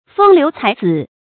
風流才子 注音： ㄈㄥ ㄌㄧㄨˊ ㄘㄞˊ ㄗㄧˇ 讀音讀法： 意思解釋： 風度瀟灑，才學出眾的人 出處典故： 唐 元稹《鶯鶯傳》：「清潤潘郎玉不如，中庭蕙草雪消初。